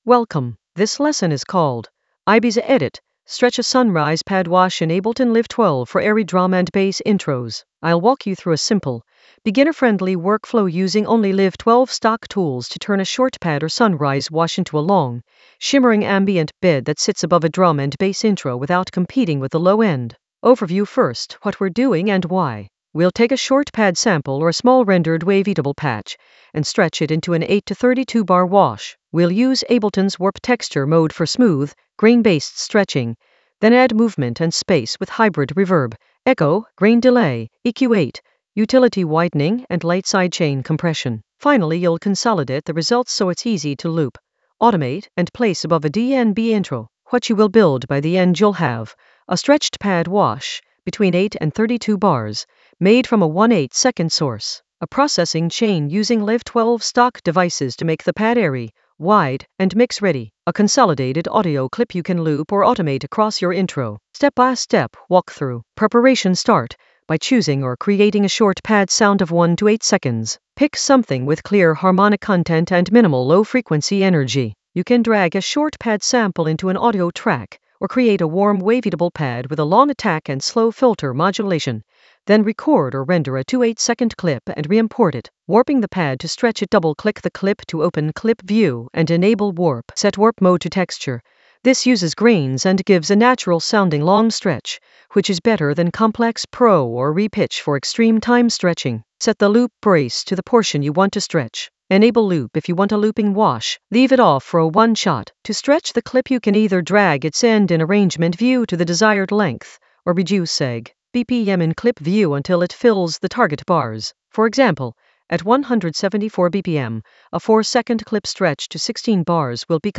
An AI-generated beginner Ableton lesson focused on Ibiza edit: stretch a sunrise pad wash in Ableton Live 12 for airy drum and bass intros in the Atmospheres area of drum and bass production.
Narrated lesson audio
The voice track includes the tutorial plus extra teacher commentary.